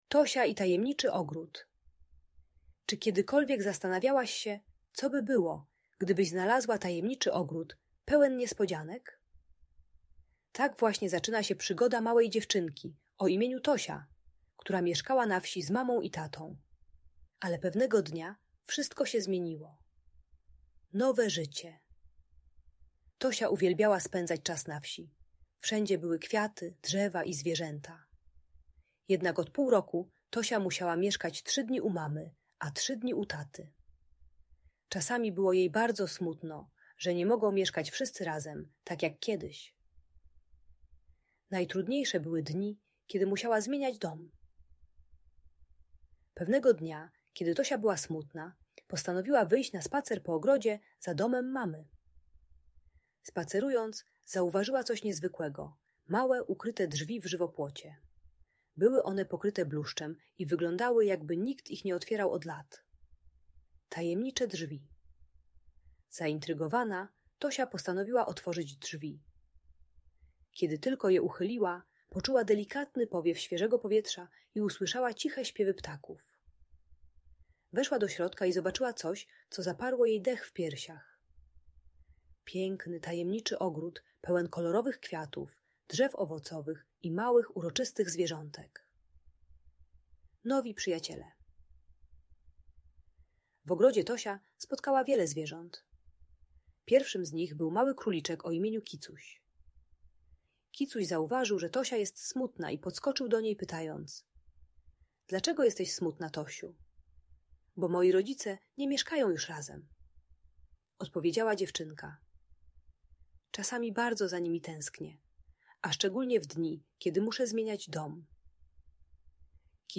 Tosia i Tajemniczy Ogród - magiczna opowieść pełna przygód - Audiobajka